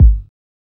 KICK KNOCK.wav